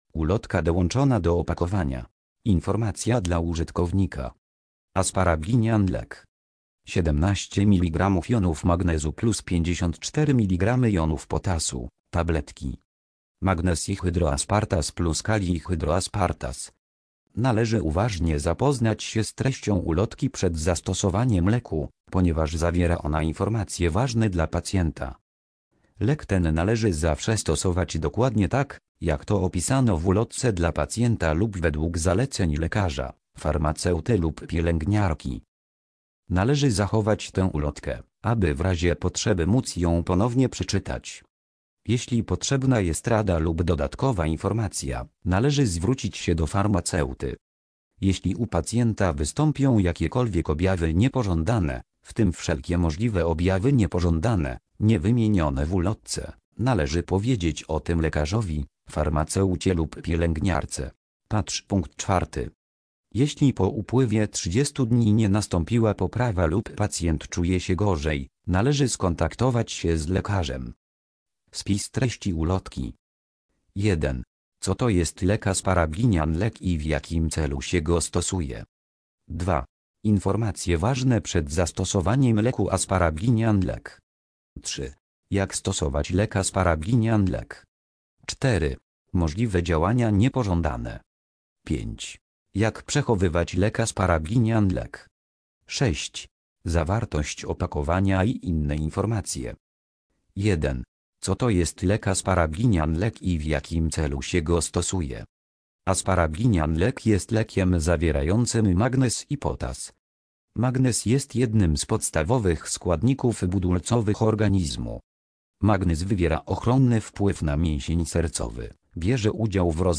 Ulotka do odsłuchania